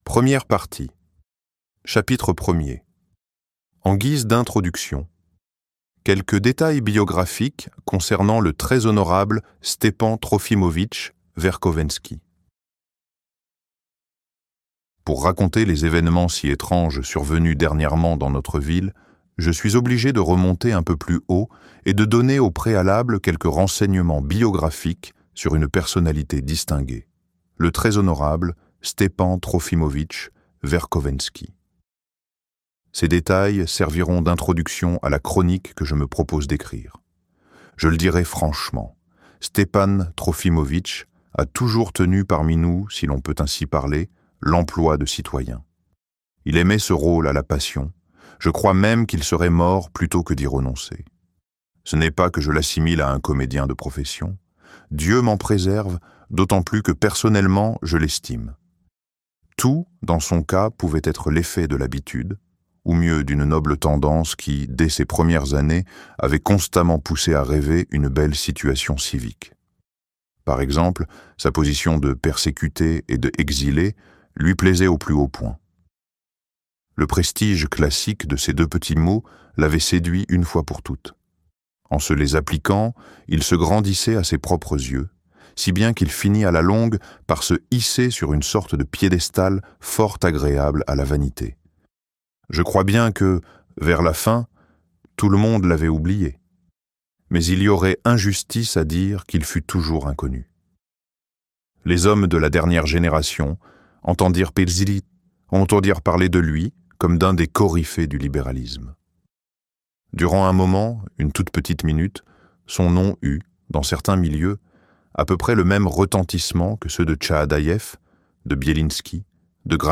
Les possédés - Livre Audio